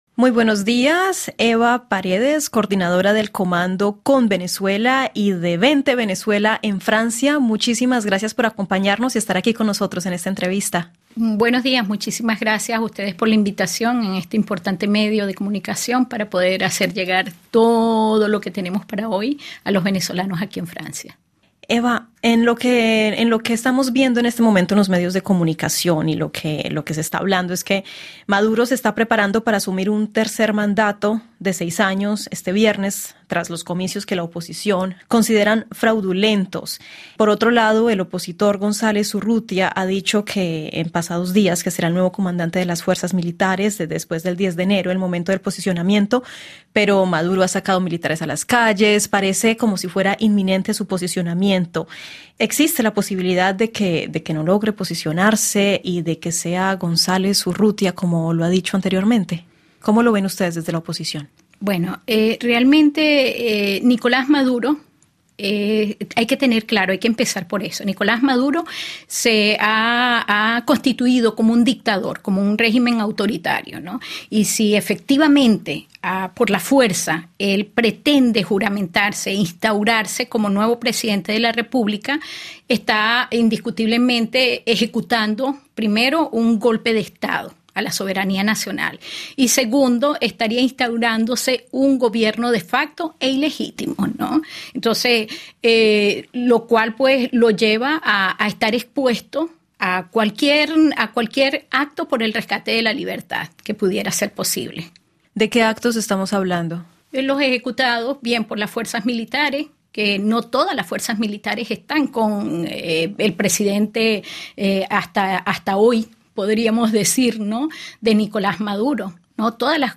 Episodio en casa